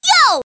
One of Daisy's voice clips in Mario Kart 7